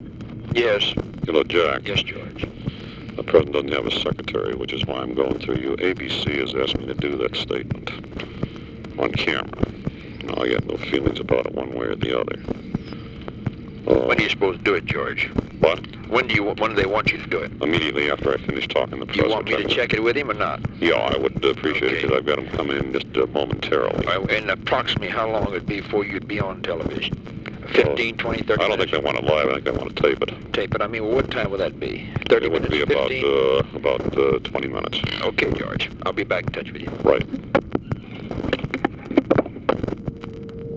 Conversation with GEORGE REEDY
Secret White House Tapes